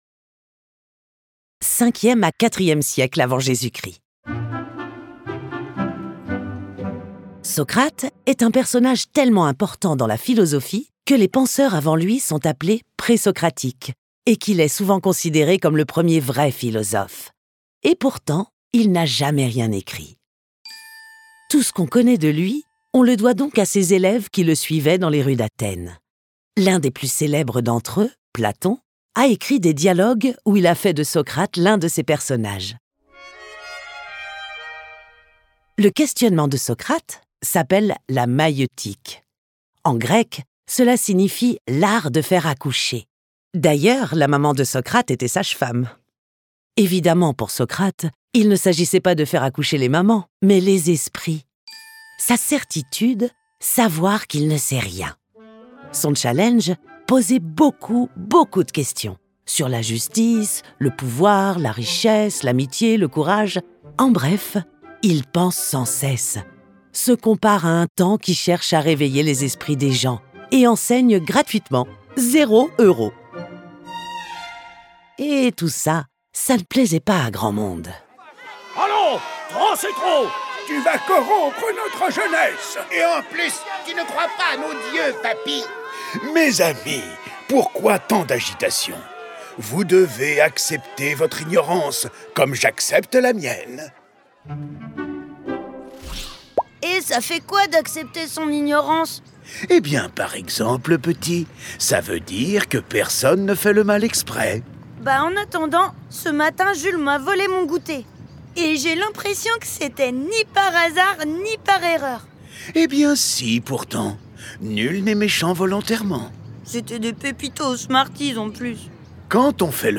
Dans cette BD audio, une lecture chorale musiquée et bruitée, les philosophes ont encore beaucoup à apprendre aux petits... comme aux grands !